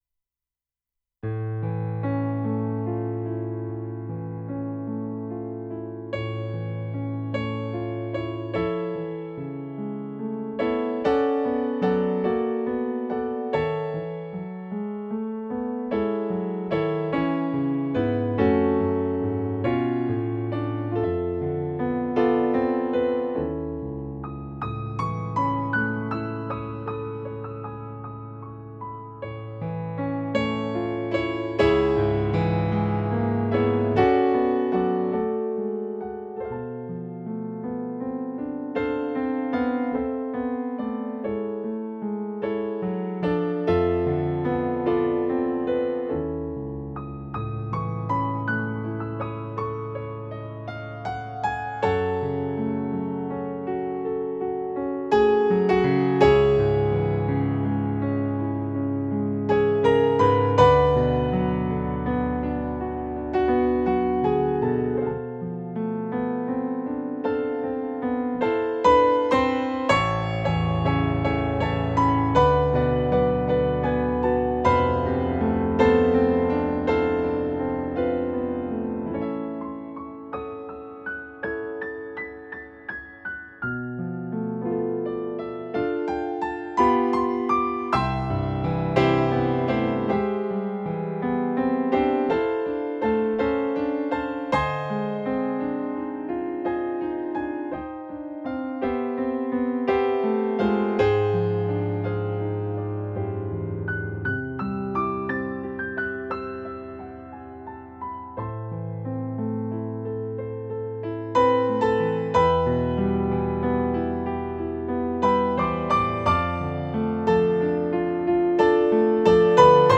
Piano Solo
I just wanted to create a new arrangement that I can play for piano of what I think is one of the most beautiful Christmas carols. When I recording myself playing it, the tempo gets faster and faster.